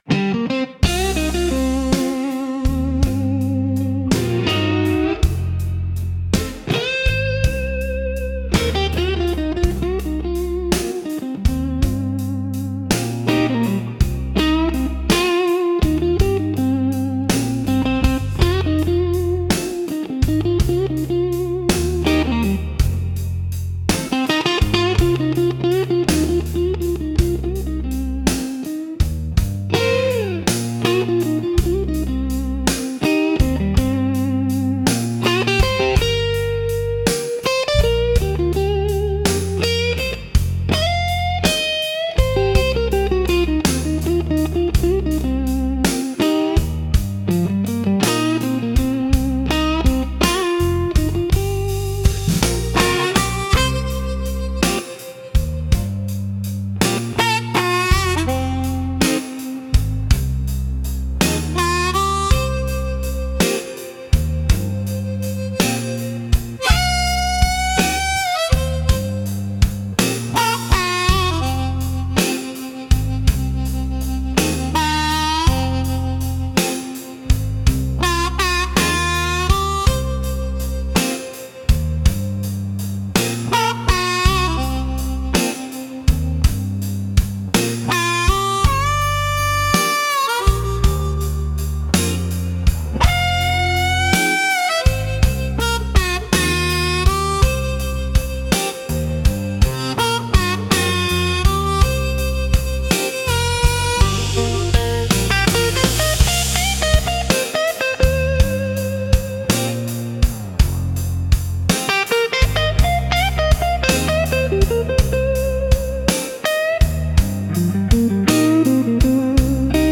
しっとりとした雰囲気を求める場面で活用されるジャンルです。